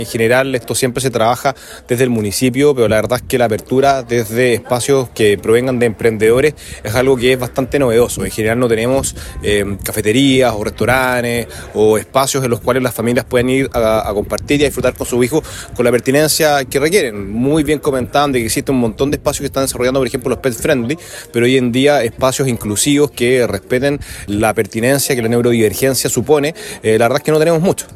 Juan Pablo Spoerer, alcalde de San Pedro de la Paz destacó el valor de la apertura de un espacio inclusivo, y cómo éste fortalece a la comunidad.